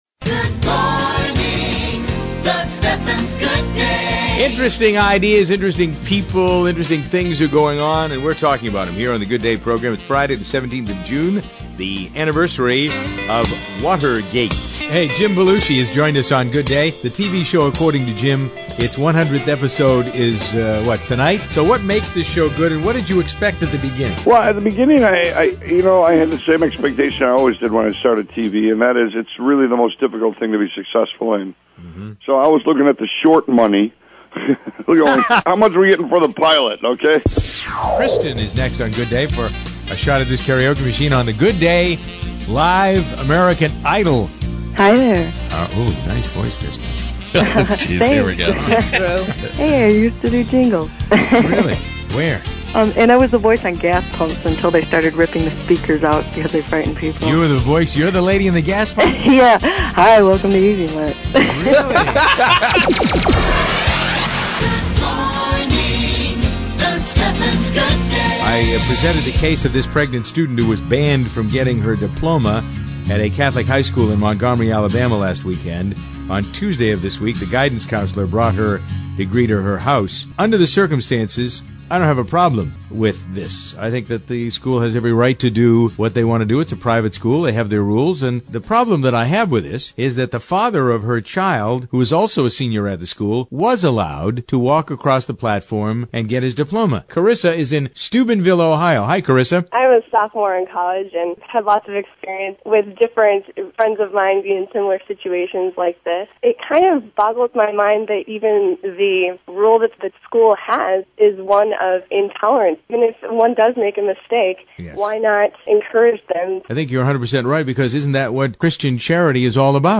Click here for the GOOD DAY mp3 demo
An upbeat way for your listeners to start their day, Good Day has fun with the issues and is grown-up talk you can listen to with your kids in the car.